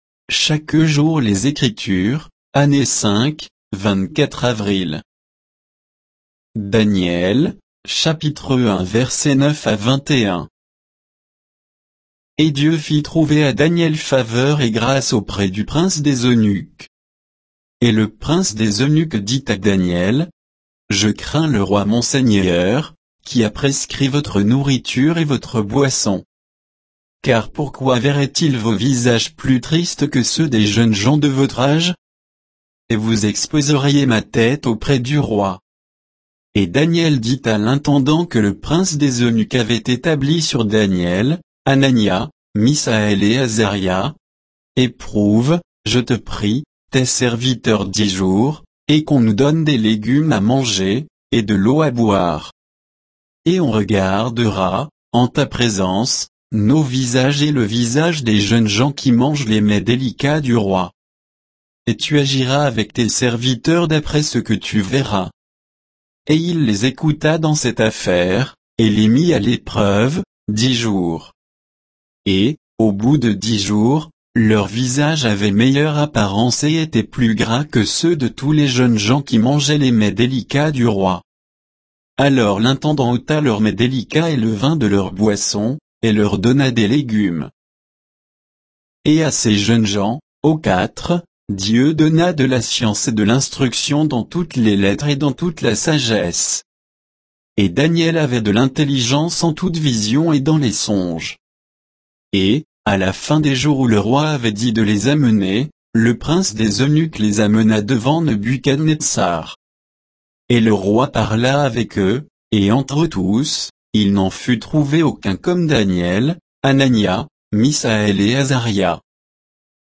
Méditation quoditienne de Chaque jour les Écritures sur Daniel 1